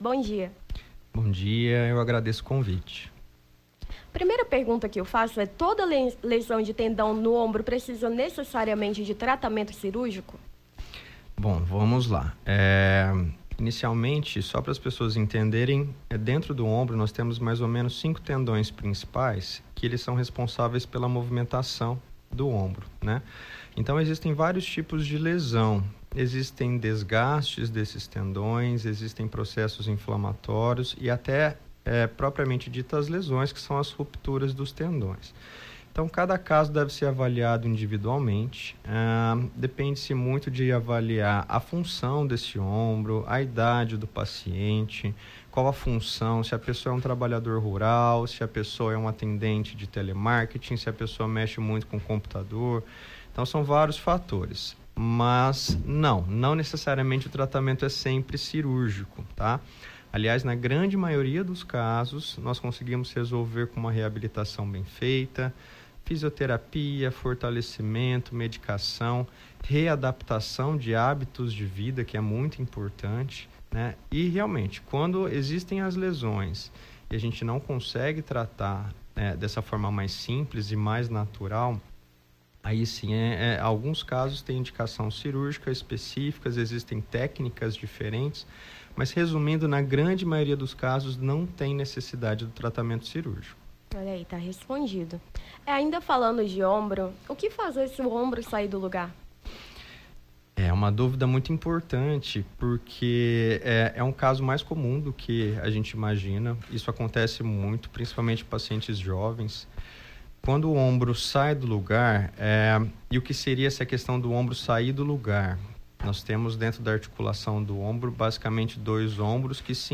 Nome do Artista - CENSURA - ENTREVISTA (HERNIA DE DISCO) 22-06-23.mp3